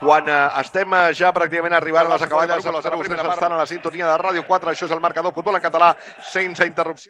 Identificació del programa i de l'emissora en una de les transmissions de futbol
Esportiu